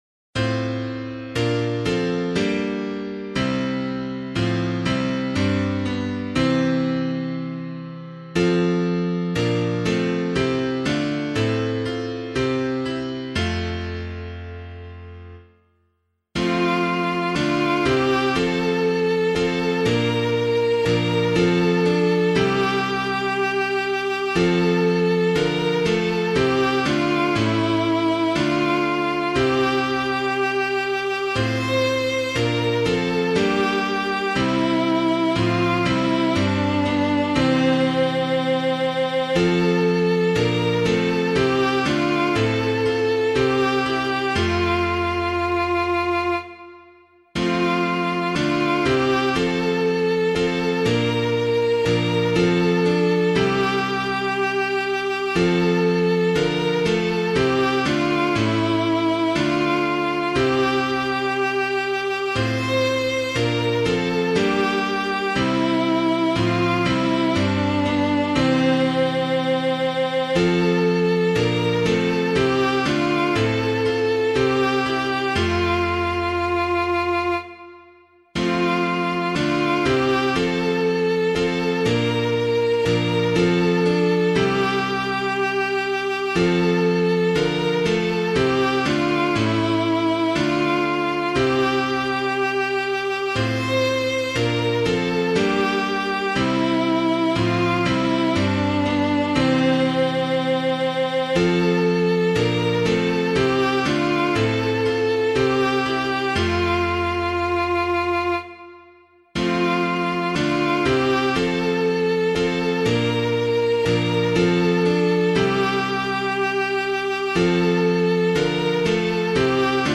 piano
Sower and Seed of Man's Reprieving [Knox - LES COMMANDEMENS DE DIEU] - piano.mp3